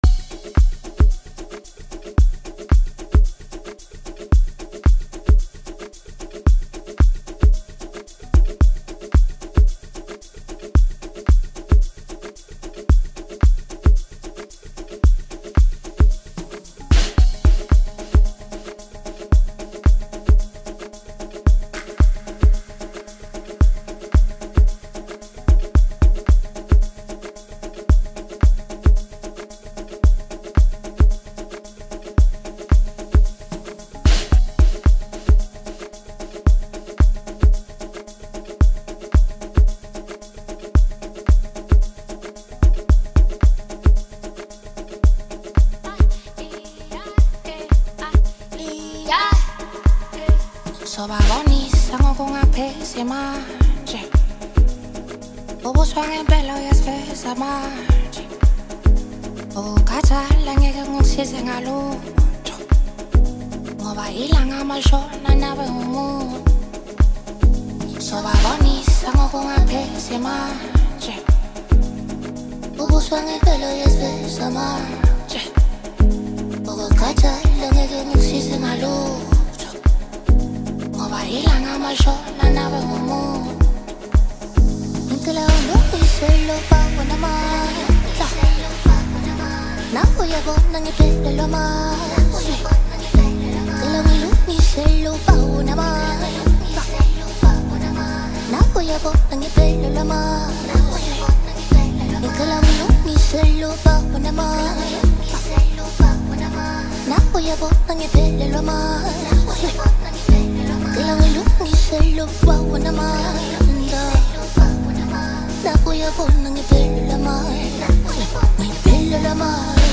A well-known South African Amapiano singer